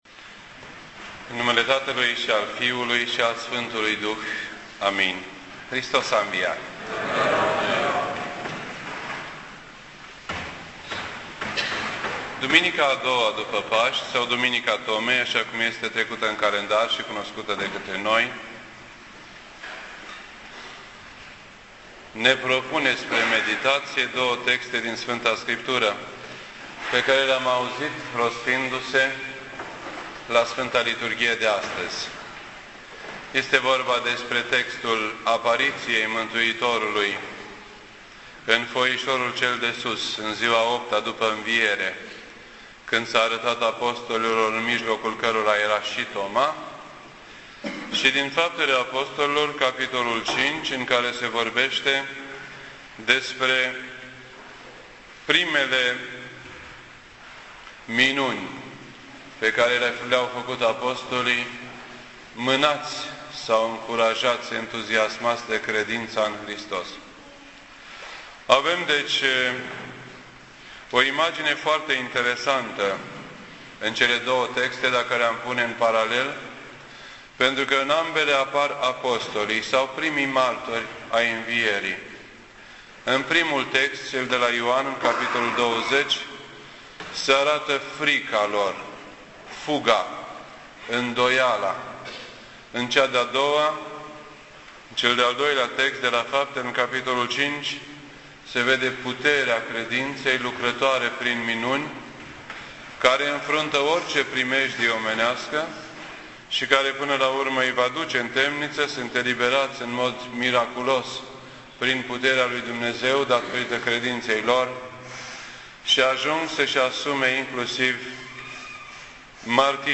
2010 at 7:38 PM and is filed under Predici ortodoxe in format audio .